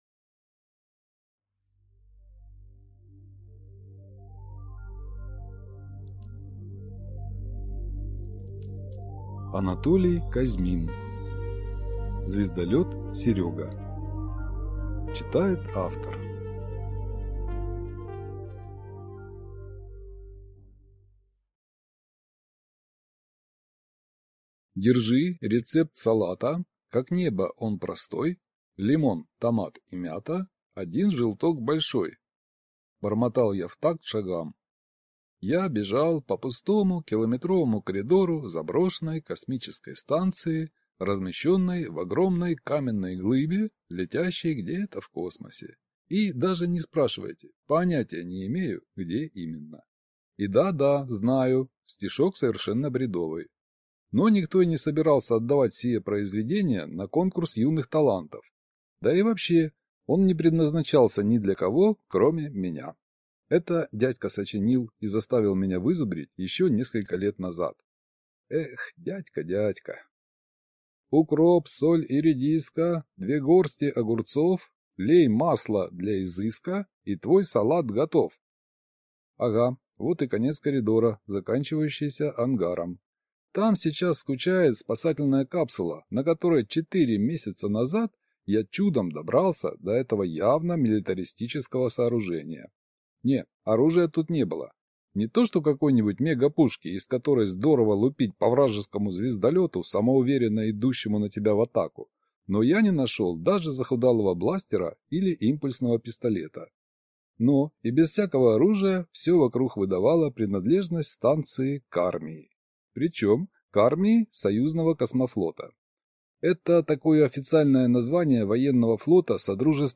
Аудиокнига Звездолёт «Серёга» | Библиотека аудиокниг